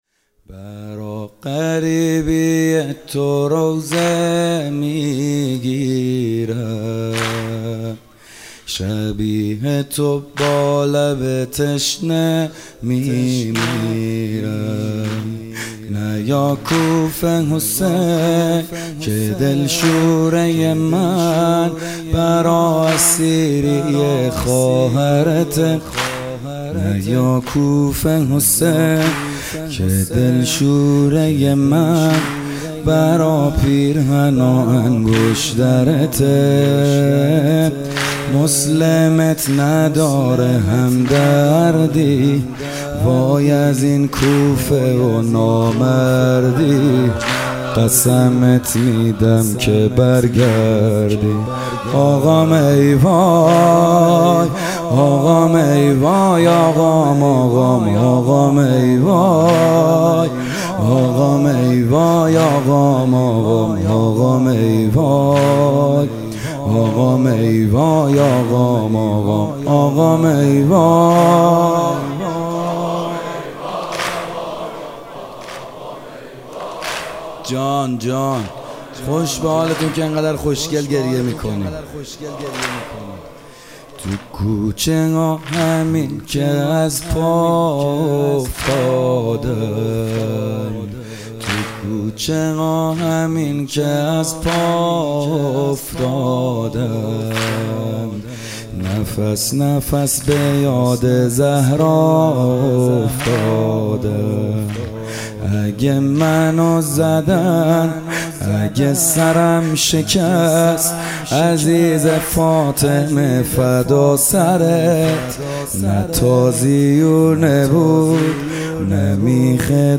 اولین روز از مـــراســم عـــزاداری دهــه اول مـــحــرم الـحــرام در مهدیه امام حسن مجتبی(ع
مرثیه سرایی